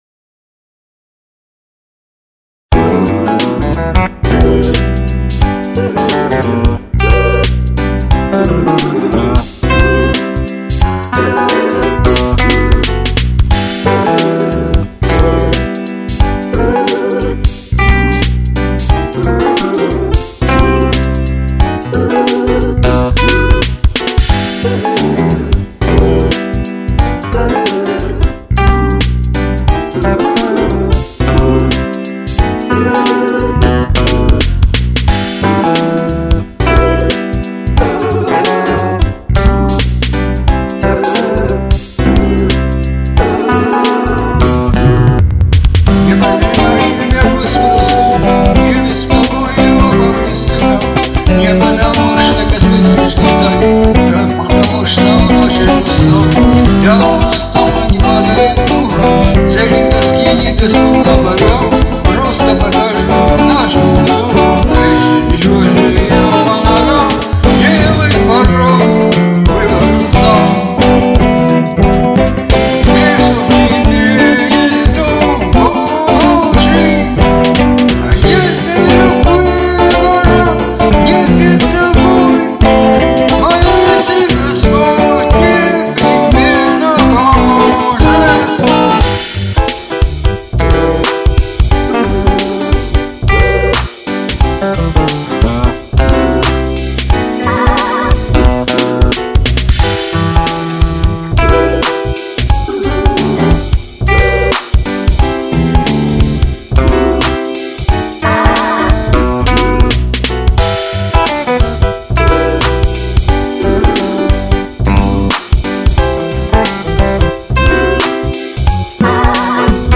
Фанк (337)